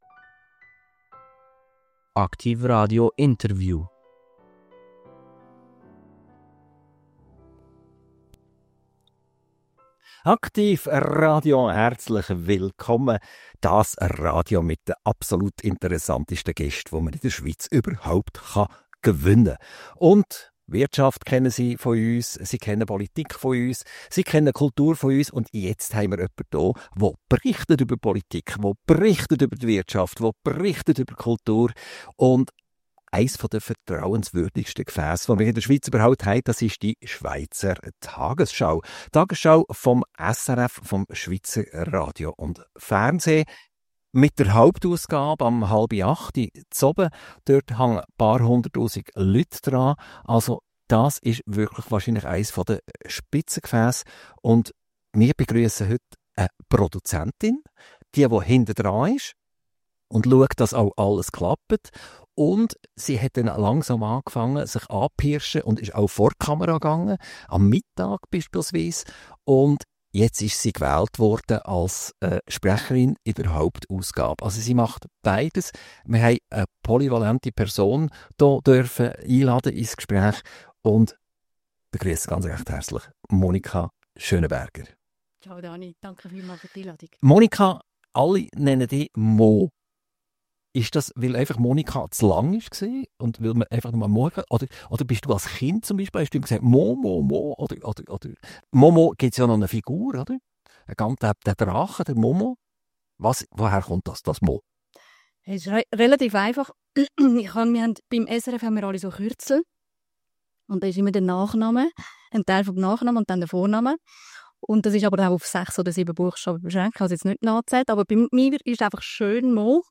INTERVIEW - Franz Grüter - 30.01.2025 – AKTIV RADIO – Podcast